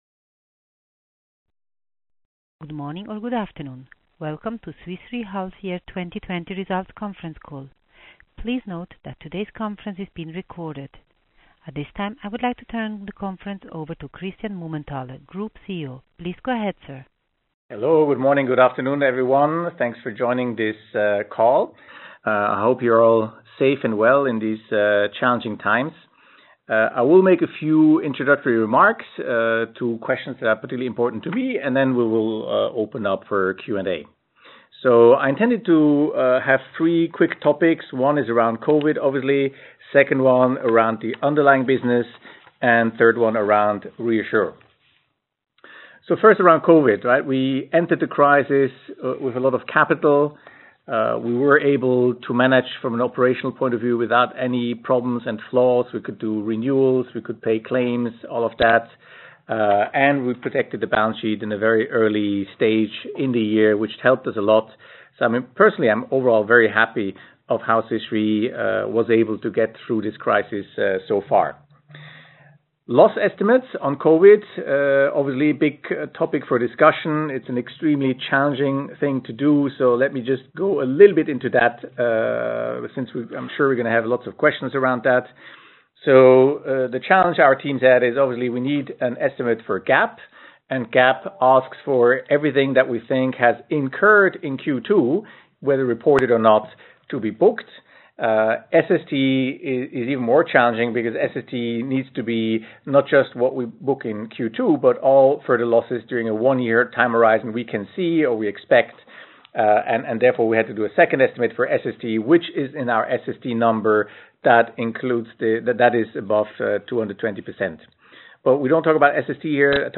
hy-2020-call-recording.mp3